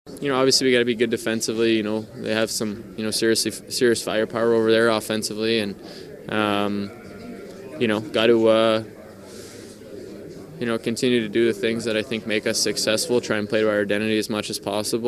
U.S. center Jack Eichel says Canada will be a tough matchup.